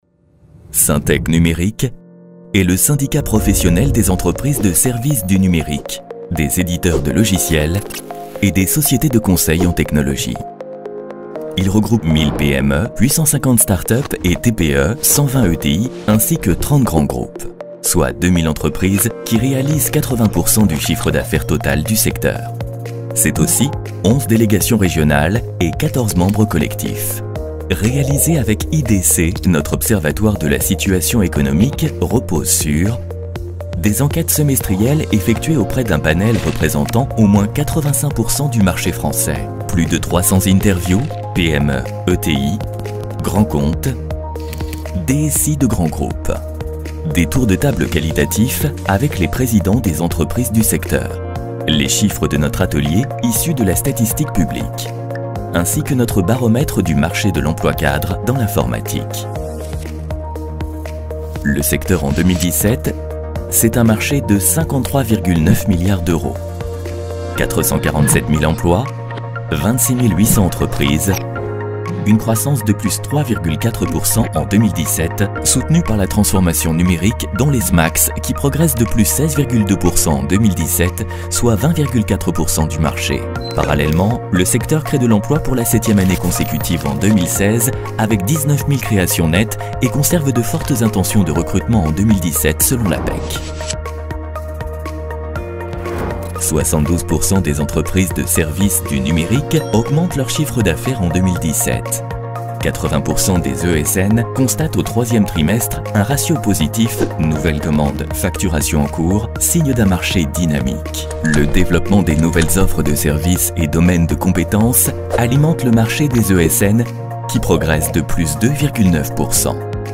SYNTEC voix grave, droit - Comédien voix off
SYNTEC voix grave, droit.